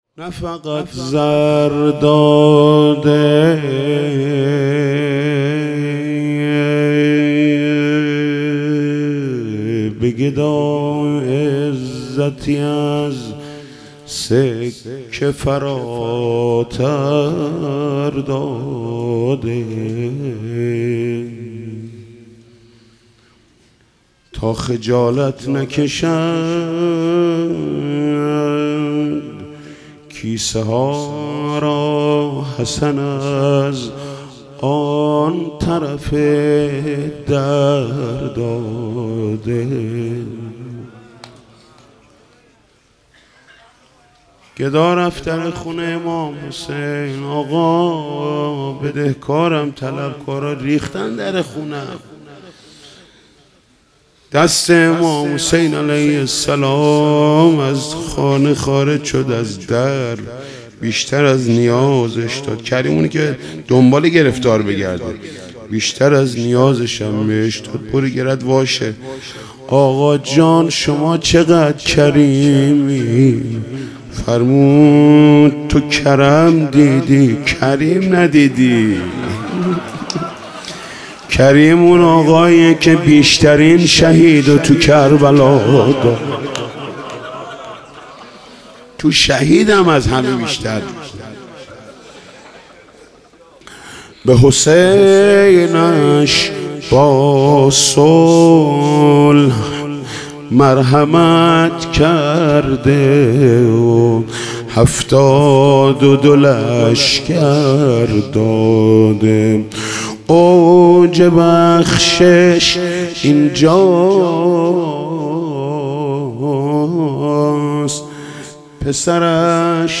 محمود کریمی؛شب ششم محرم98/روضه
هیئت رایت العباس (ع)